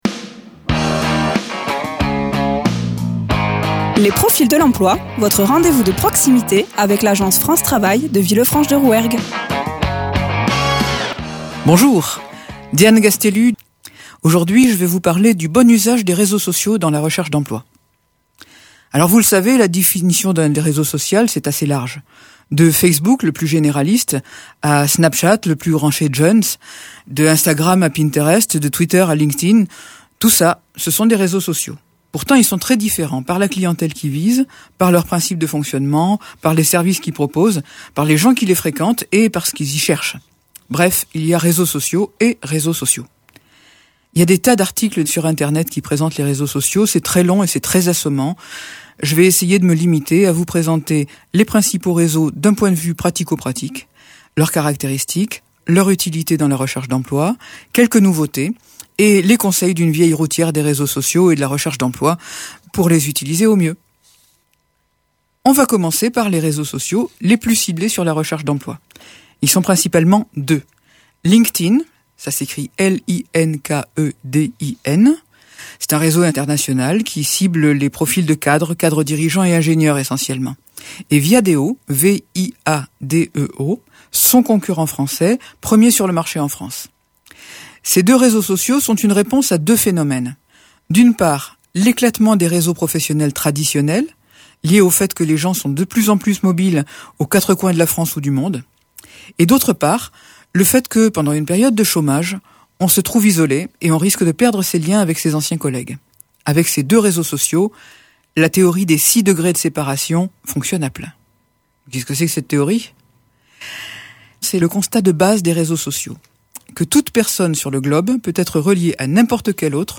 Interviews
Présenté par Les conseillers de France Travail, CFM villefranche